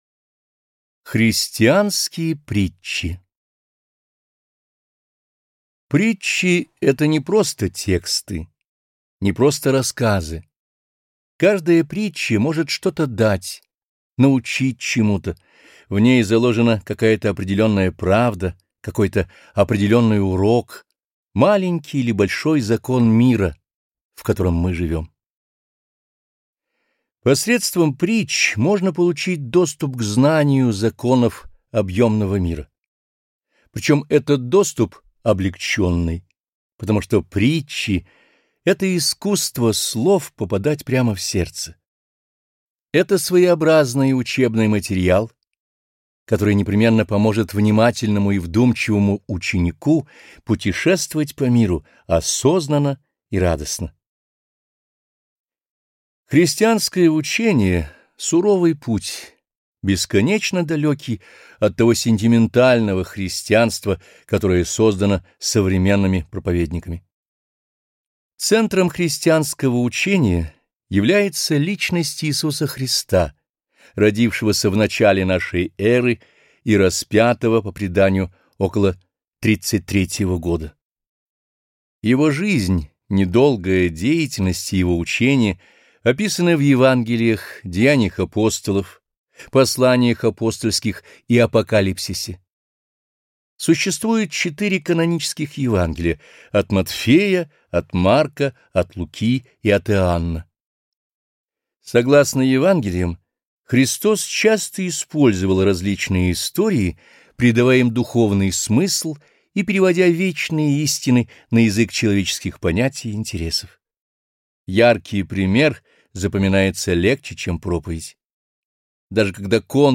Аудиокнига Христианские притчи | Библиотека аудиокниг